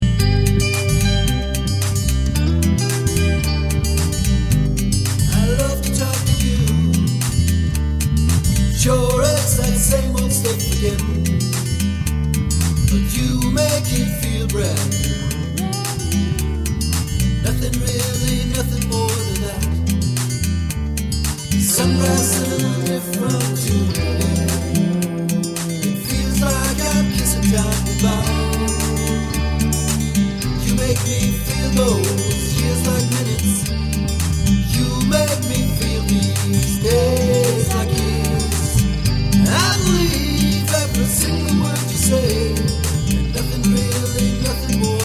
acoustic guitar
drums: QY-20